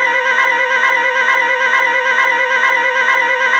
I don’t know, it’s like 8.89 beats per second. I got 4 pulses in 450ms, then mathed up to 1 second.
I have an 89 Sabers Hilt on the bench suffering with warbly sound.